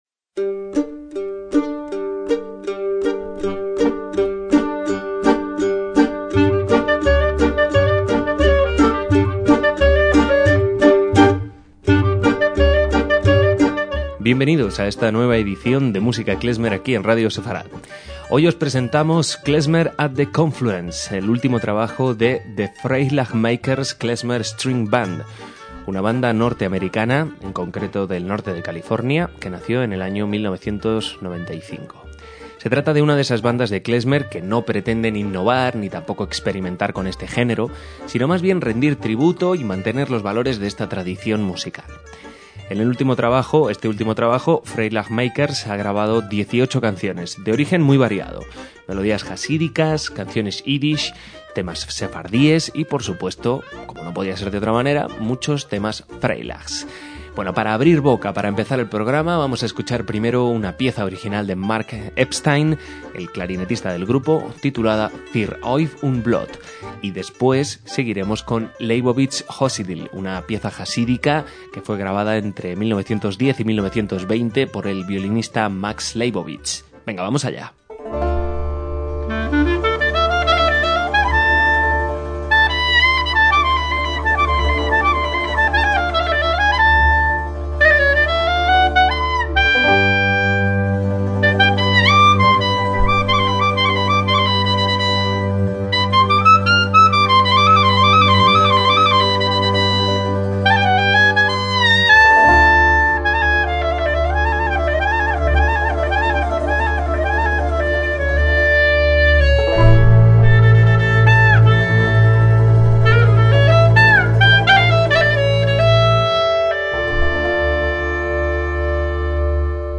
MÚSICA KLEZMER
instrumentos de cuerda